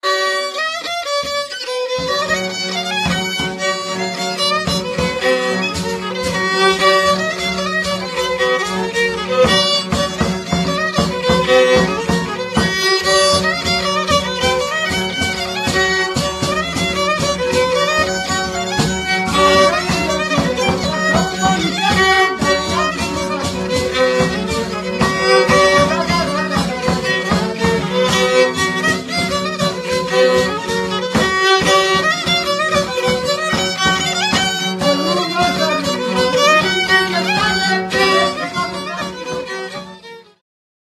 Mazurek
Badania terenowe
skrzypce
basy 3-strunowe, śpiew
bębenek